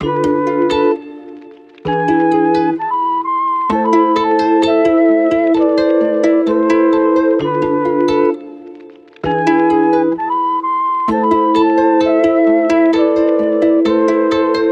MELODY LOOPS
Dialogue (130 BPM – Am)
UNISON_MELODYLOOP_Dialogue-130-BPM-Am.mp3